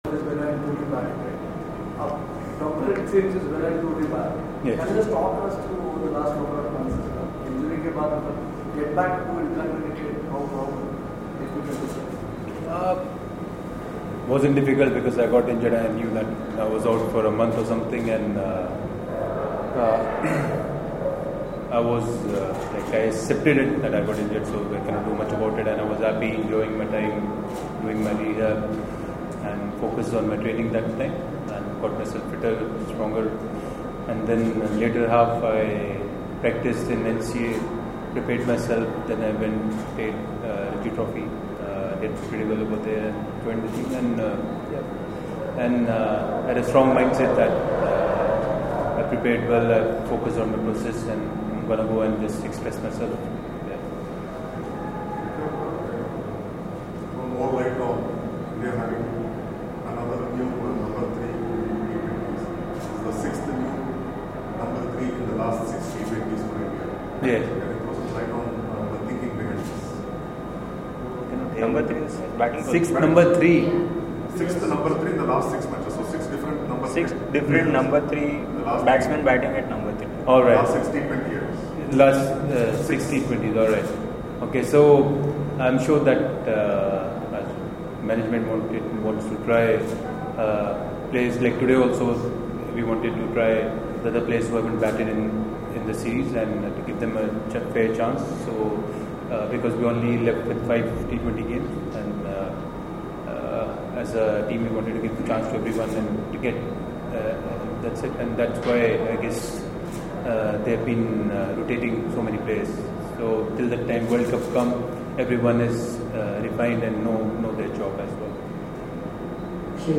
Shikhar Dhawan spoke to the media in Pune on Friday after the 3rd T20I against Sri Lanka.